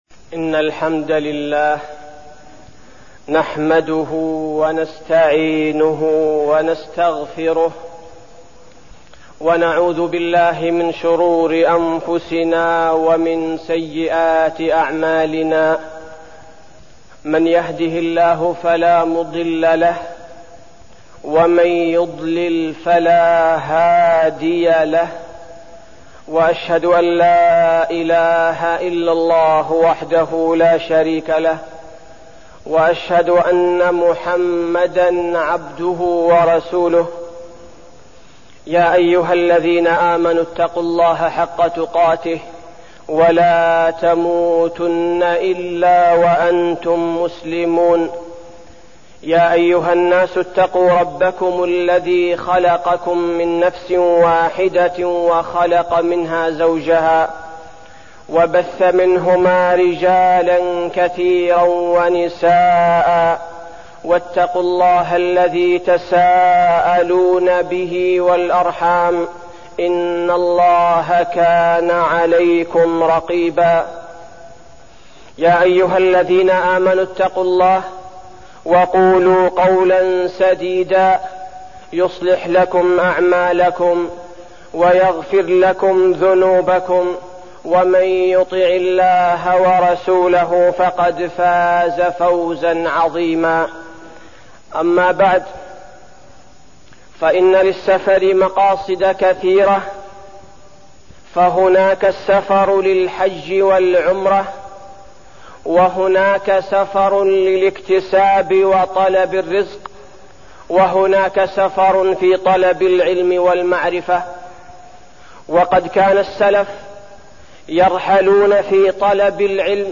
تاريخ النشر ٢٥ صفر ١٤١٩ هـ المكان: المسجد النبوي الشيخ: فضيلة الشيخ عبدالباري الثبيتي فضيلة الشيخ عبدالباري الثبيتي السفر واستغلال الأجازة